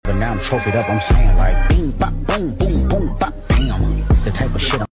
Bang！